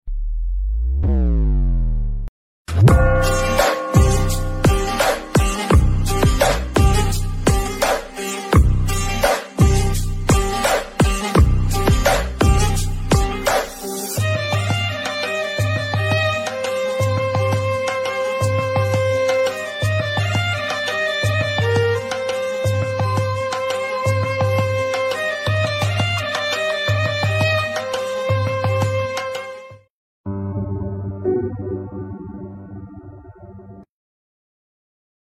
Categories BGM Ringtones